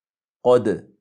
Det ska uttalas på följande sätt: